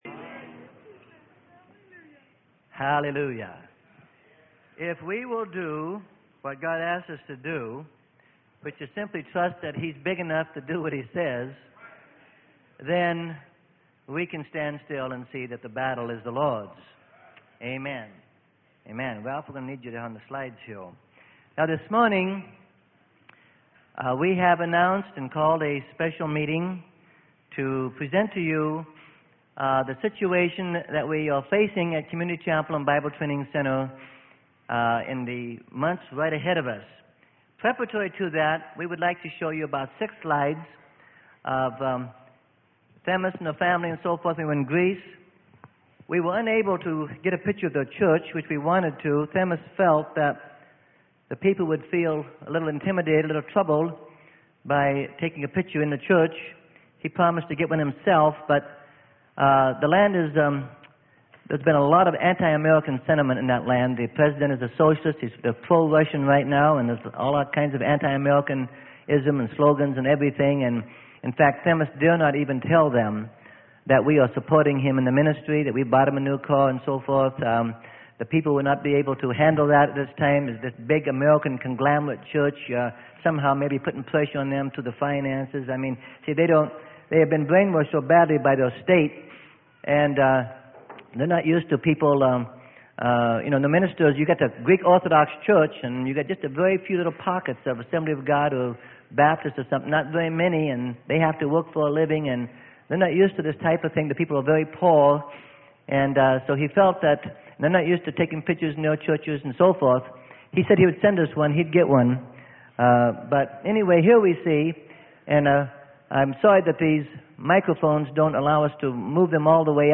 Sermon: CHURCH MINISTRY AND FINANCIAL STATUS PRESENTATION.